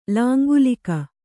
♪ lāngulika